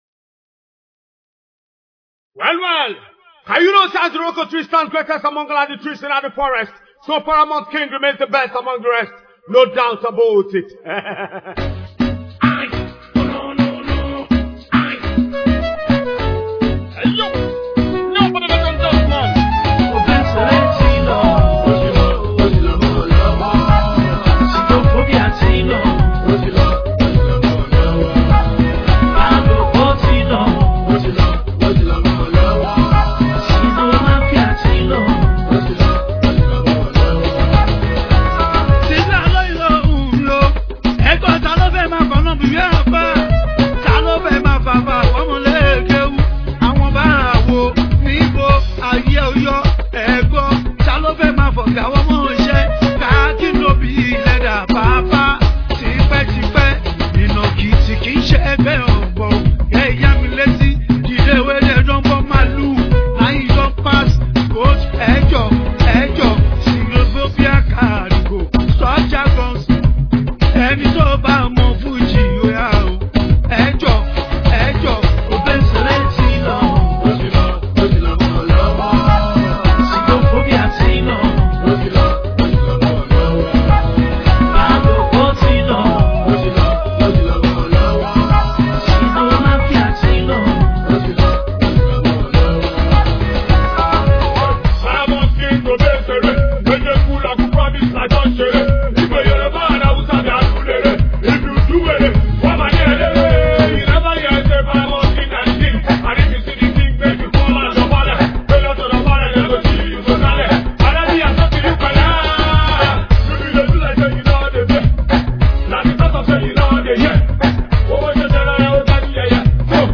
award winning hip hop singer from Nigeria